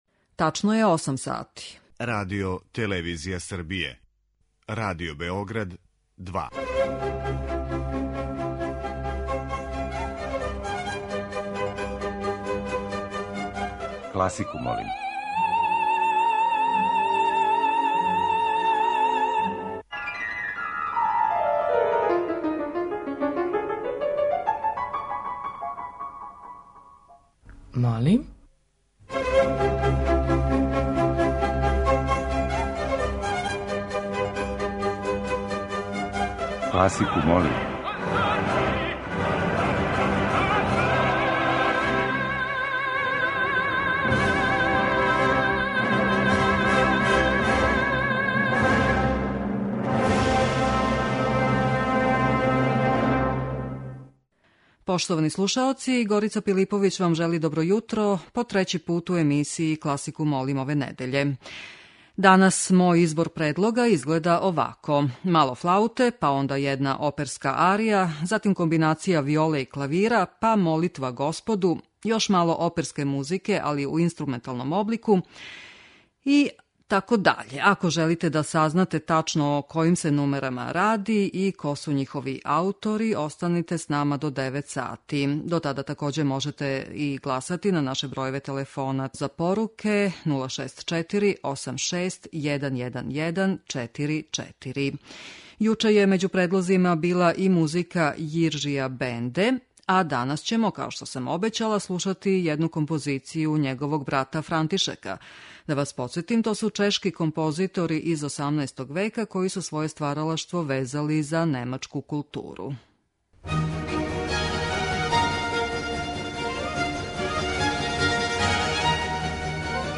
Избор за недељну топ-листу класичне музике Радио Београда 2